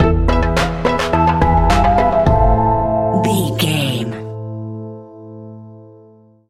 Aeolian/Minor
scary
ominous
haunting
eerie
synthesiser
electric piano
strings
drums
percussion
horror music